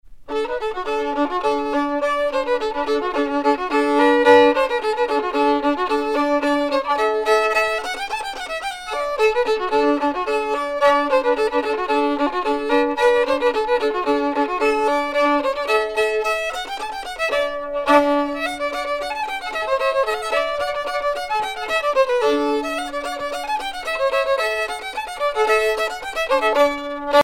danse : pas d'été
Pièce musicale éditée